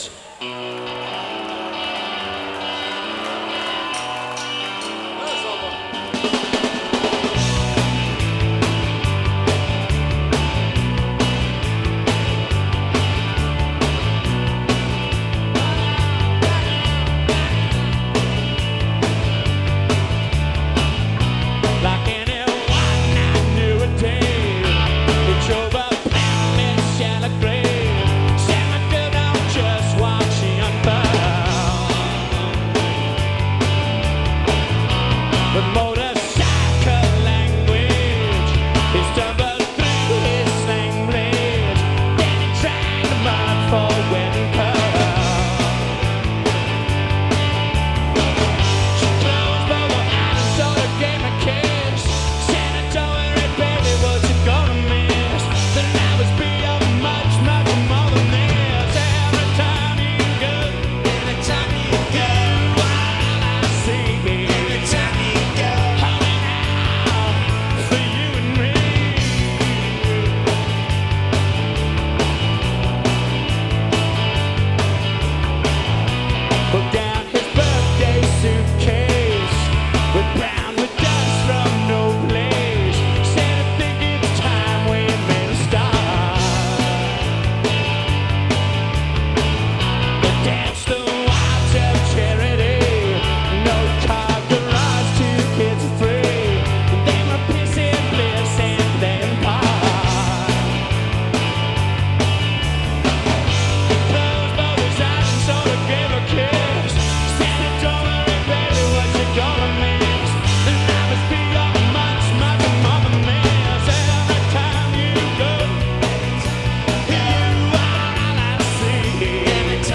Live In 1989